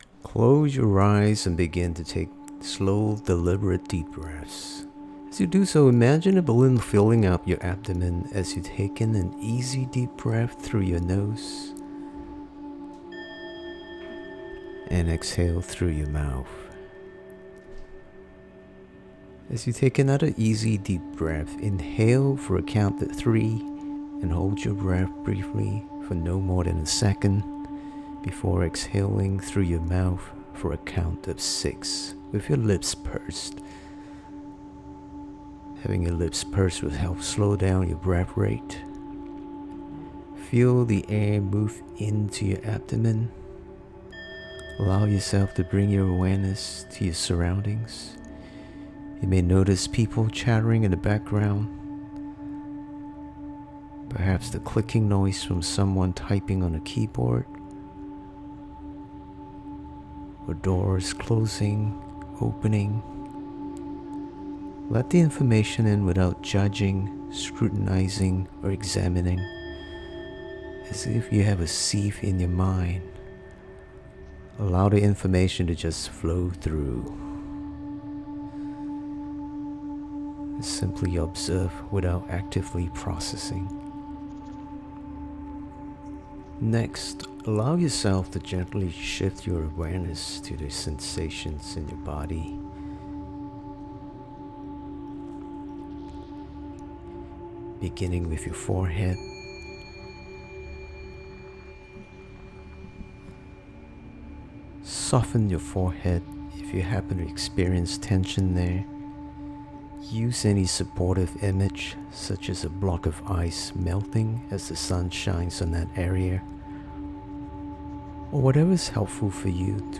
Practicing mindfulness can improve how you feel, keep stress in check and boost your productivity. Try this brief mindfulness exercise to refresh and recharge.